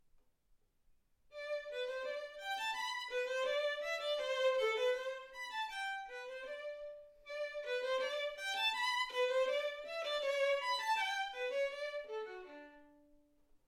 Hegedű etűdök Kategóriák Klasszikus zene Felvétel hossza 00:14 Felvétel dátuma 2025. december 8.